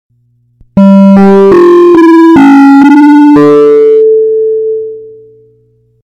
Disney Cruise Intercom Bass Booted Sound Button - Free Download & Play